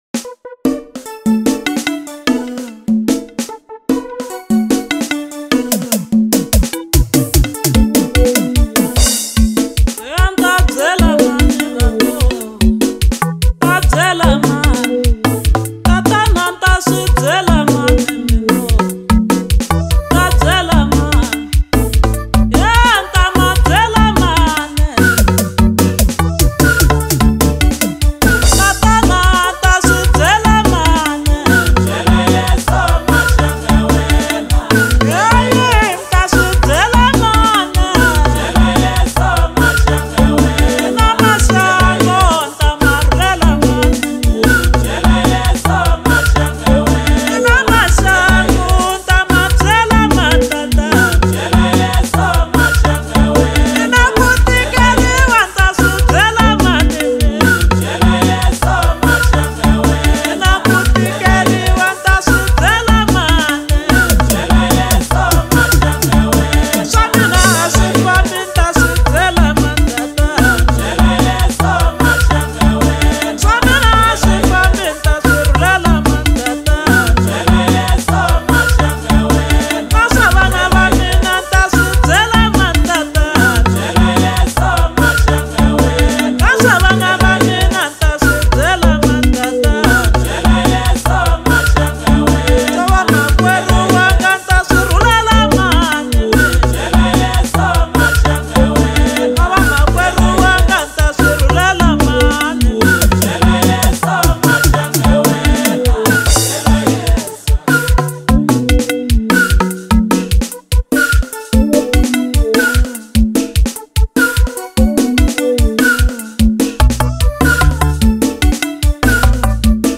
April 13, 2026 Maskandi Maskanda Maskandi Songs 0